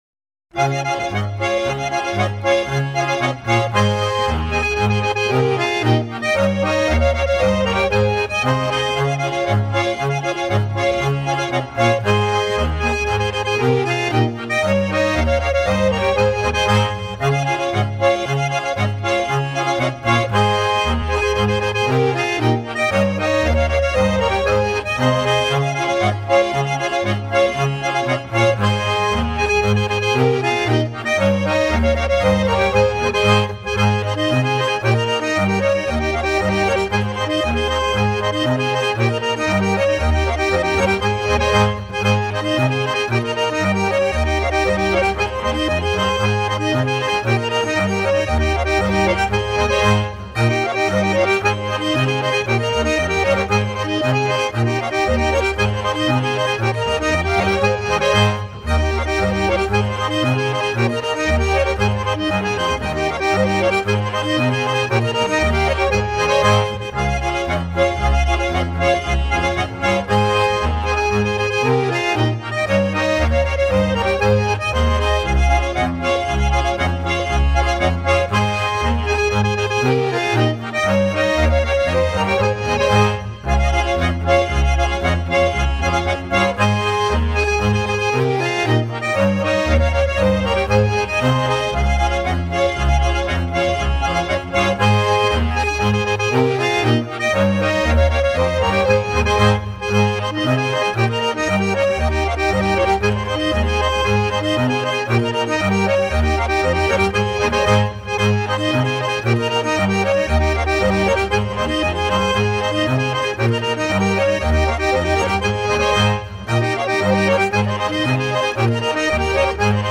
Swiss diatonic accordion music.